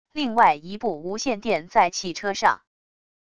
另外一部无线电在汽车上wav音频